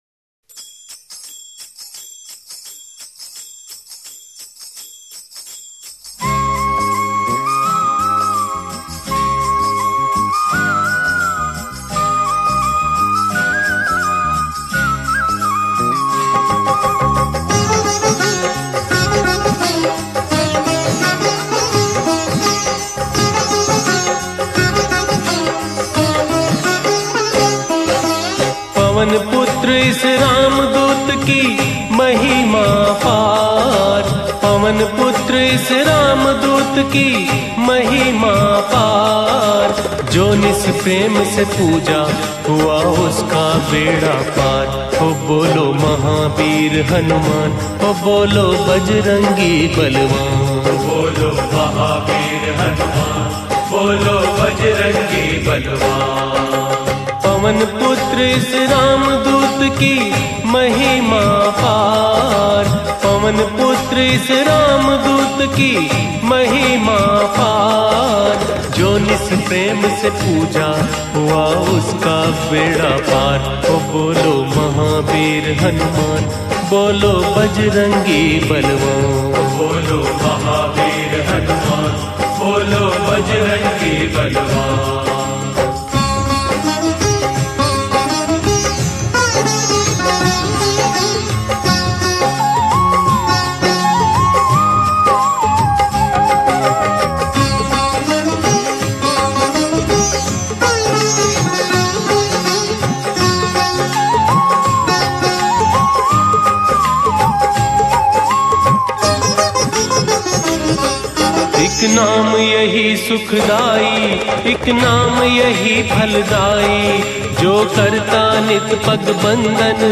Shree Hanuman Single Bhajan